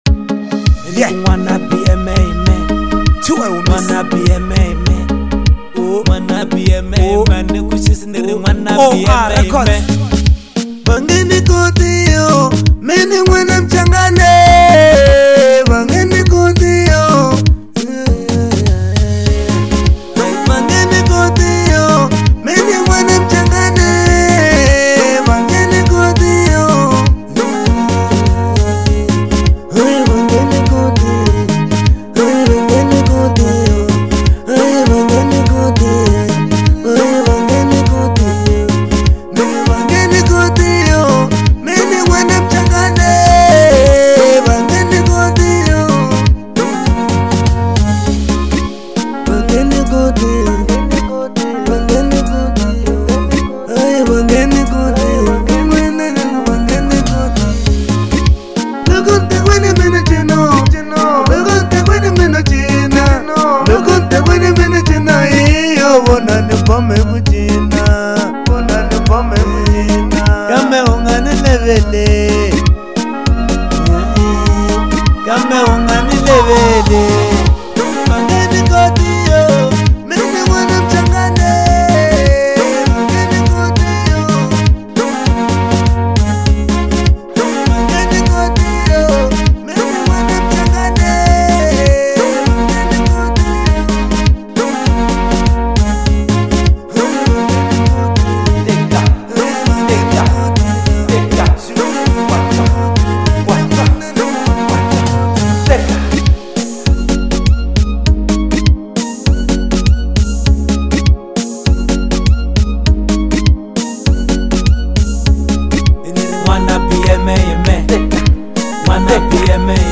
03:18 Genre : Marrabenta Size